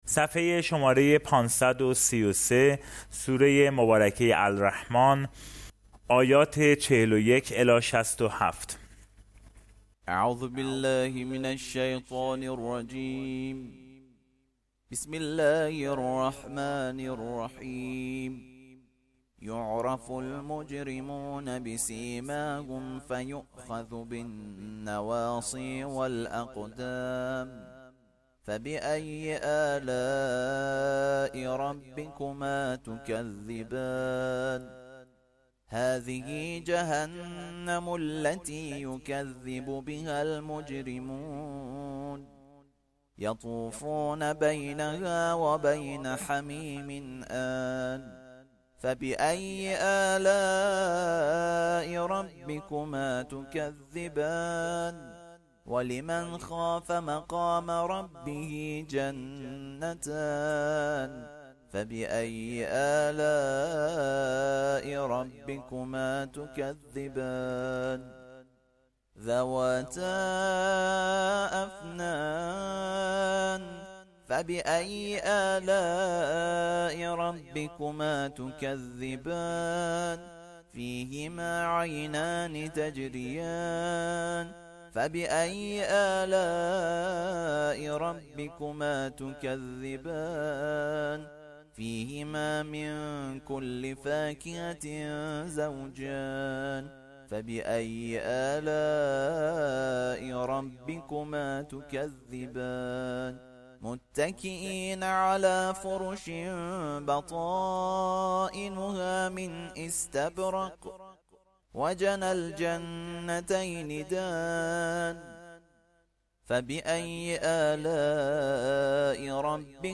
ترتیل صفحه ۵۳۳ از سوره الرحمن(جزء بیست و هفتم)
ترتیل سوره(الرحمن)